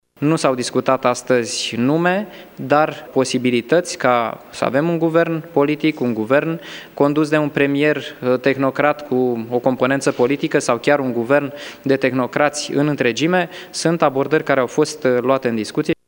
Copreședintele ALDE, Daniel Constantin, a declarat, la ieșirea de la consultări, că au fost discutate mai multe variante: